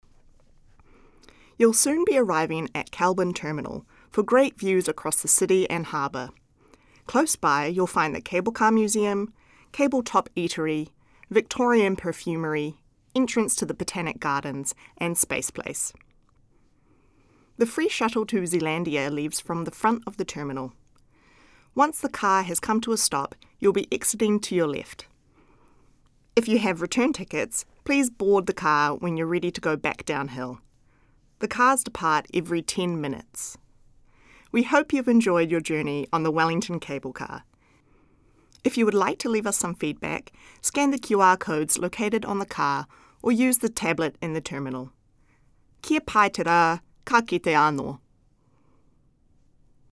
Audio Commentary
After leaving Salamanca Station.